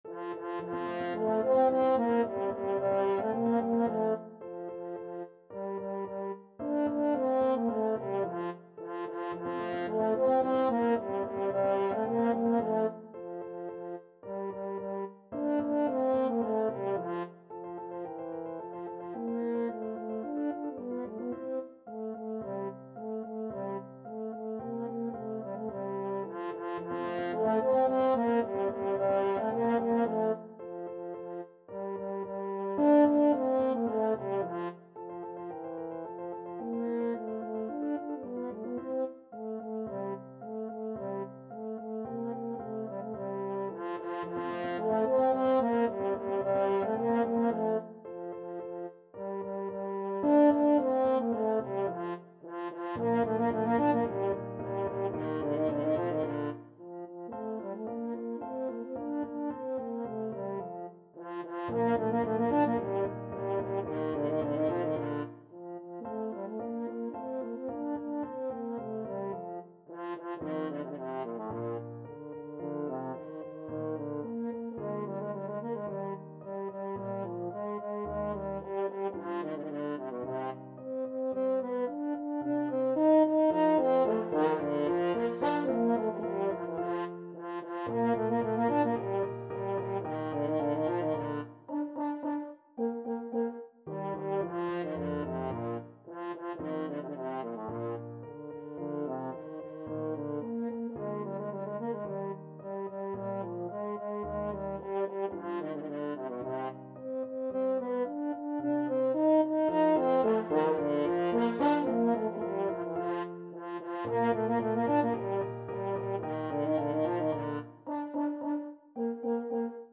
Free Sheet music for French Horn
French Horn
F major (Sounding Pitch) C major (French Horn in F) (View more F major Music for French Horn )
Vivace assai =110 (View more music marked Vivace)
2/4 (View more 2/4 Music)
A3-Eb5
Classical (View more Classical French Horn Music)